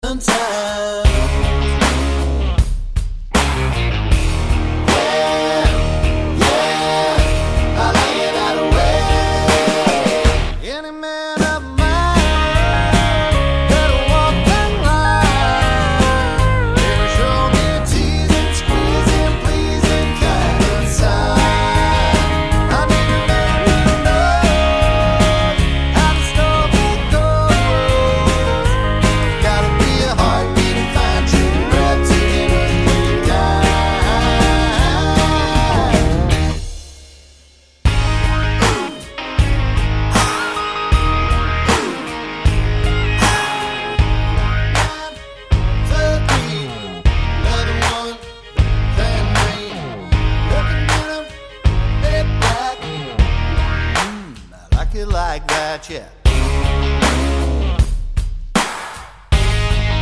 no Backing Vocals Country (Female) 4:03 Buy £1.50